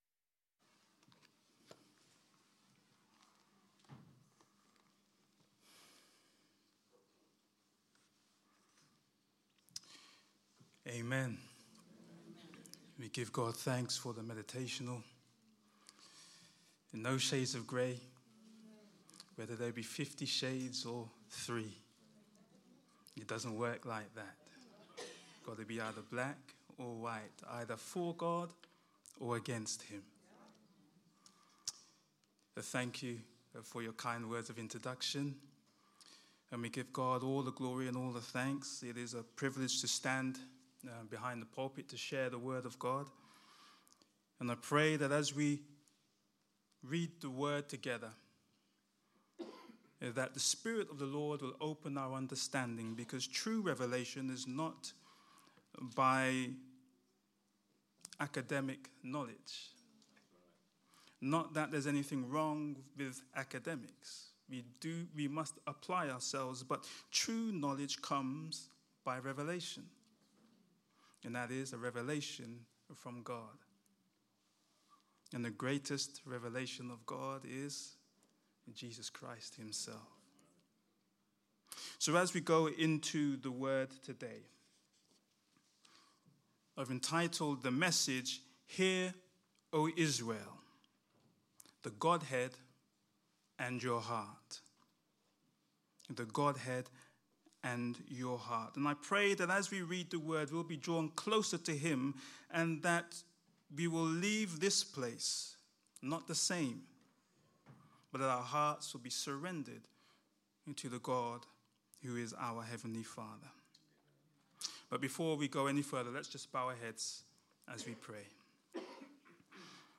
Scripture Deuteronomy 6: 4,5 An expository sermon on the Godhead.